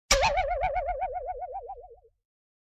Play, download and share vv_spring cartoon 2 original sound button!!!!
vv-spring-cartoon-2.mp3